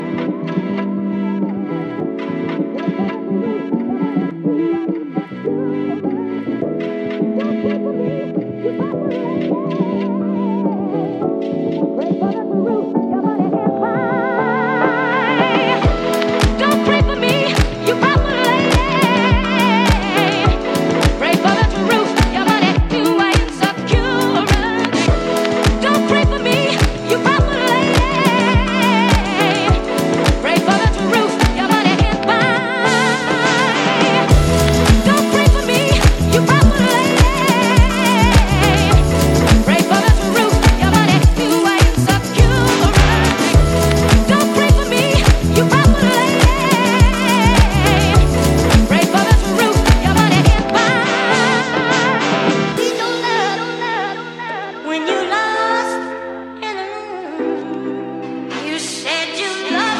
ジャンル(スタイル) ELECTRONICA / DANCE / NU JAZZ / FUNK / SOUL